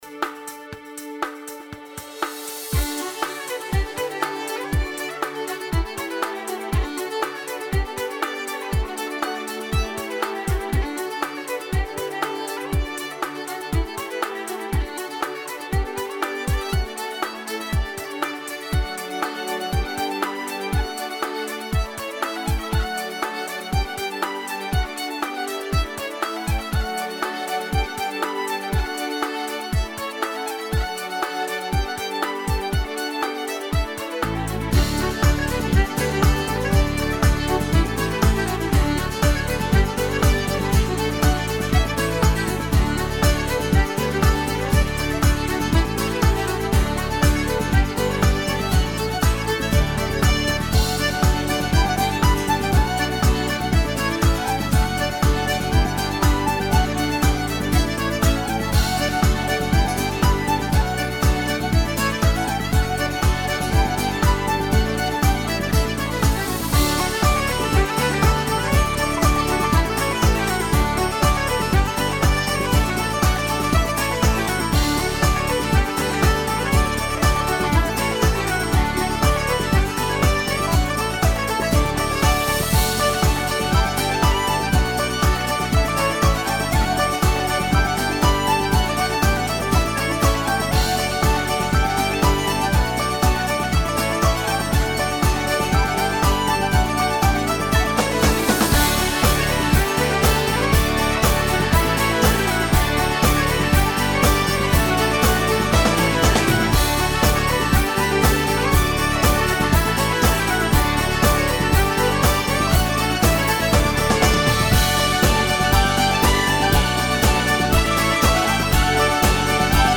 Celtic part
Intro : démarrage sur les violons